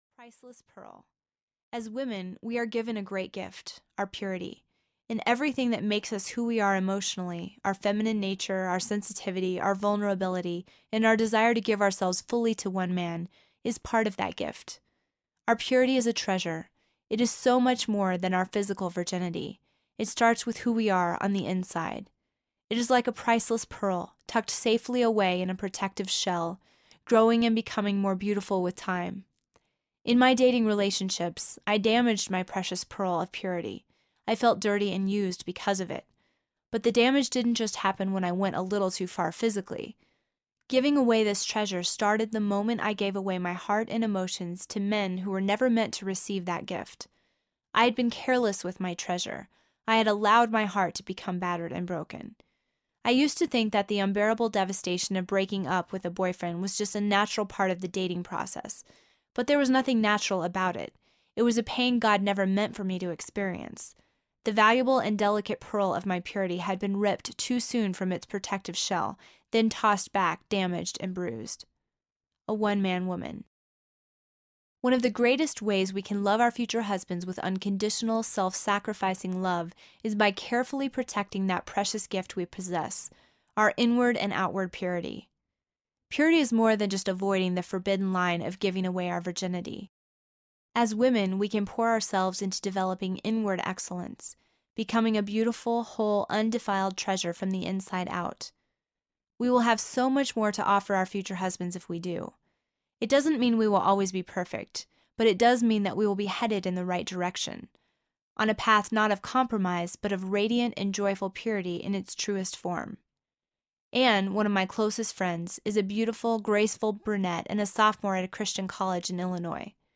When God Writes Your Love Story Audiobook